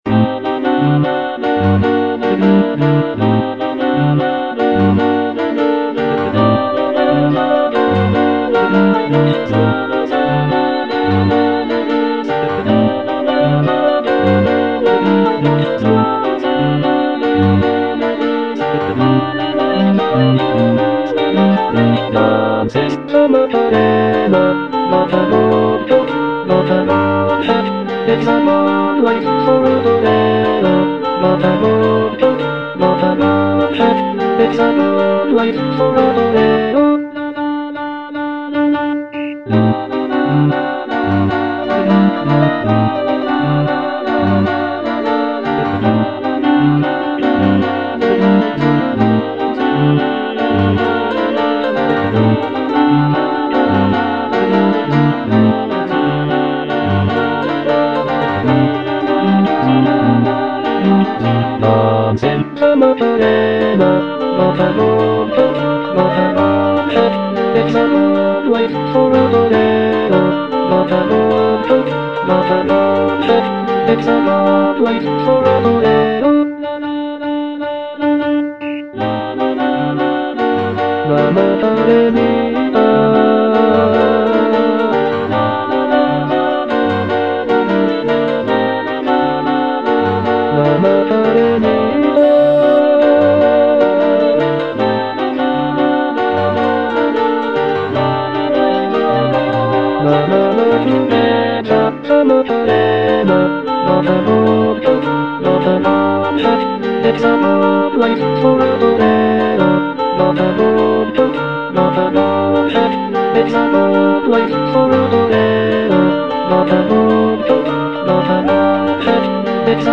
(bass I) (Emphasised voice and other voices) Ads stop